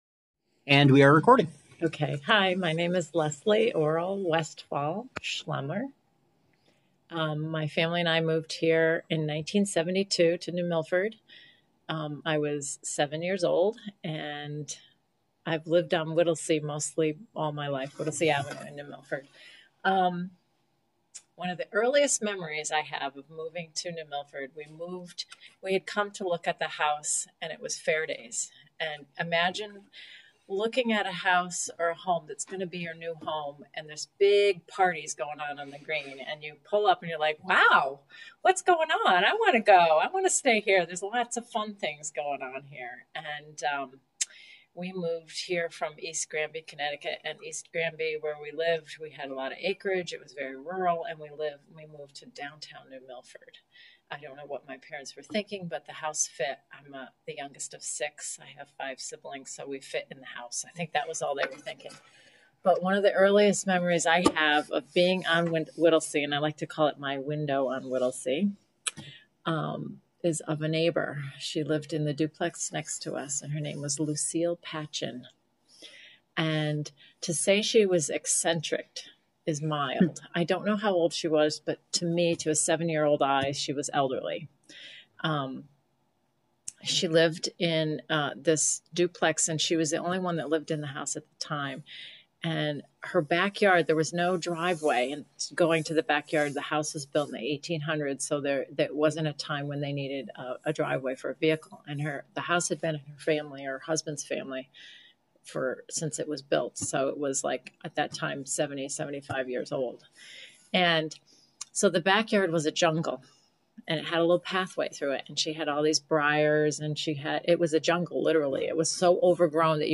Oral History
Location New Milford Public Library